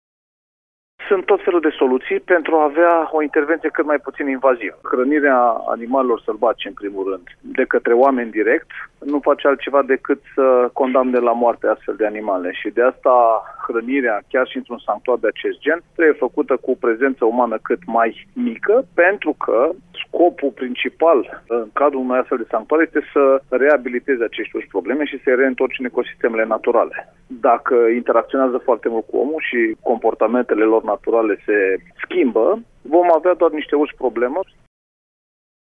Pentru hrănirea animalelor vor fi achiziționate funiculare, mai spune Ionuț Banciu: